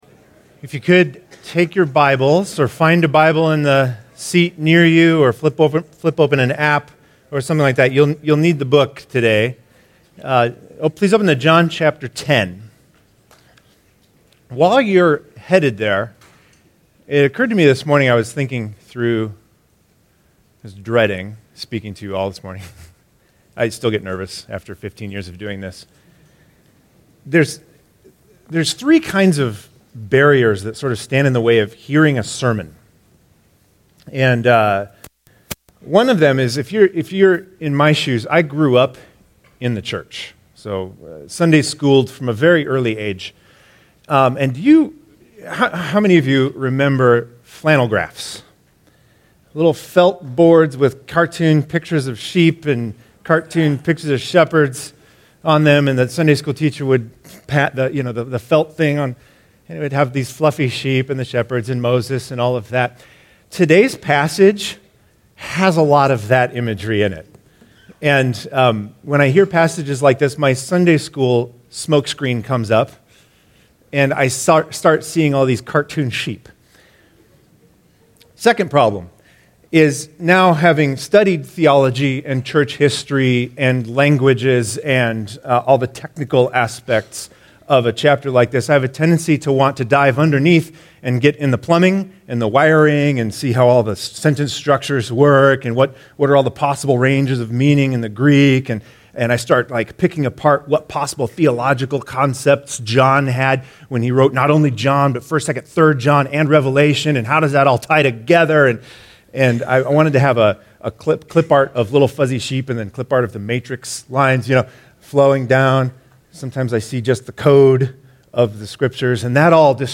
Download sermon notes and discussion questions: (PDF, DOC) Today we continue our study of John in chapter 10.